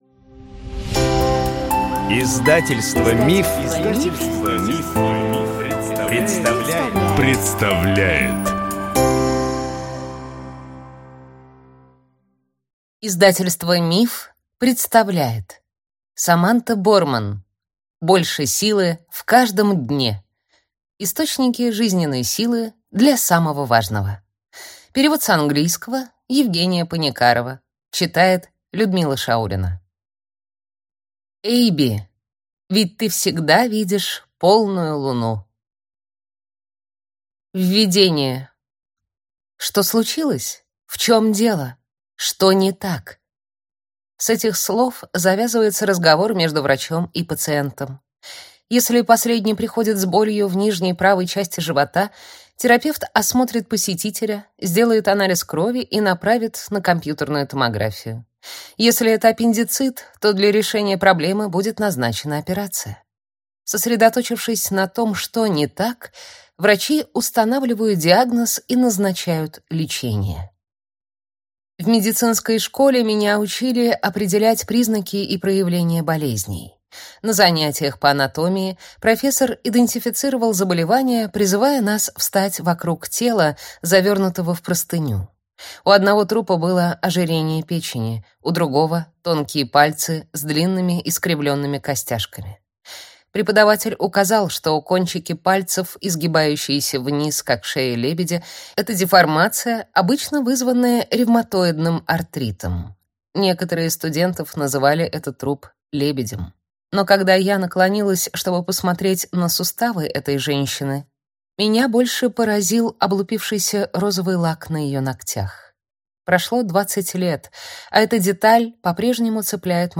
Аудиокнига Больше силы в каждом дне. Источники жизненной силы для самого важного | Библиотека аудиокниг